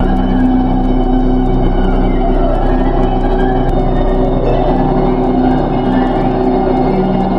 PHONK SAMPLE
描述：mnisphere + gross beat
标签： 130 bpm Hip Hop Loops Pad Loops 1.24 MB wav Key : Unknown
声道立体声